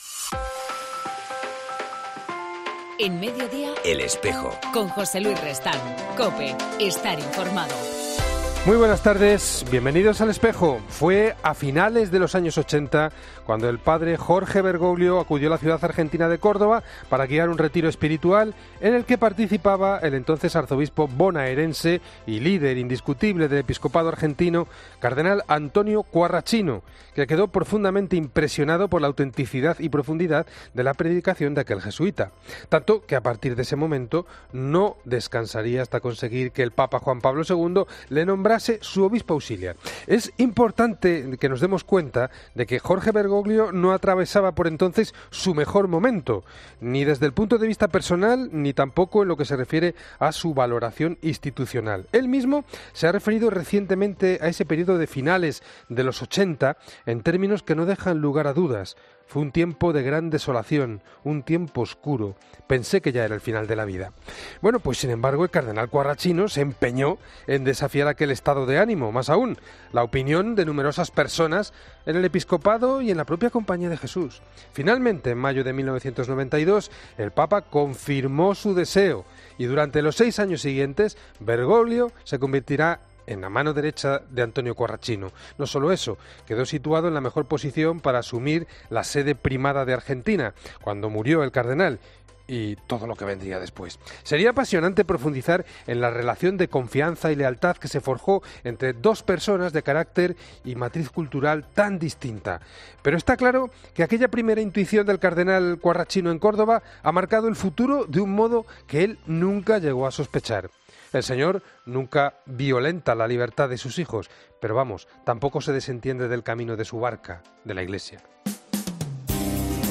En El Espejo del 13 de marzo entrevistamos al vaticanista Andrea Tornielli